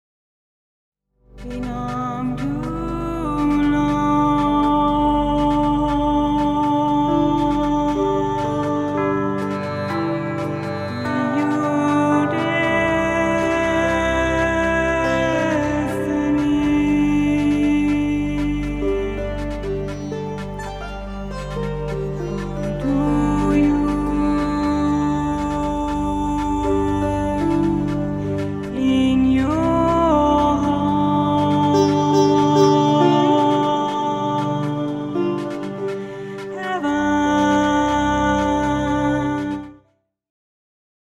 Meditative / Poesie
Momentum-Aufnahmen